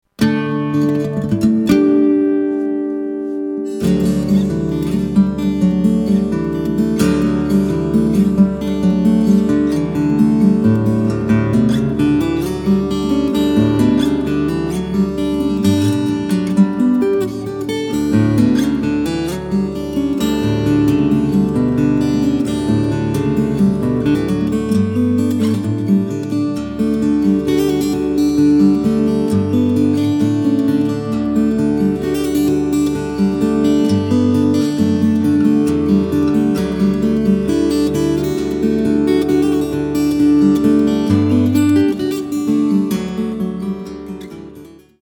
Genre: Slack-key guitar.